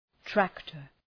Προφορά
{‘træktər}